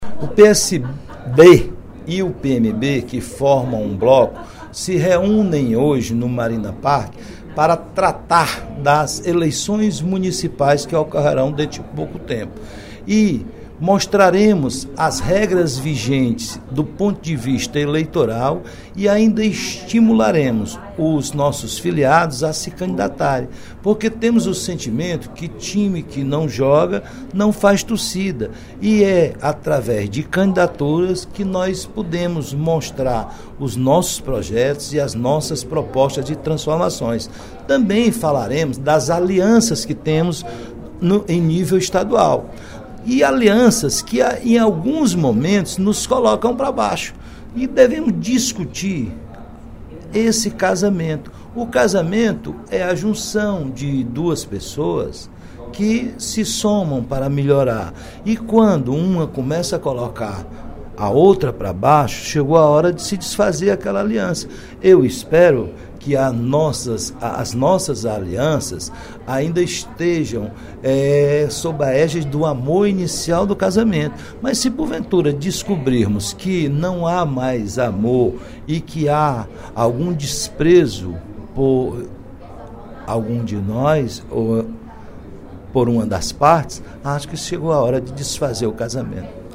O deputado Roberto Mesquita (PSD) ressaltou, durante o primeiro expediente da sessão plenária da Assembleia Legislativa desta sexta-feira (10/06), a reunião do bloco PSD e PMB para debater com as lideranças as eleições à Prefeitura Municipal.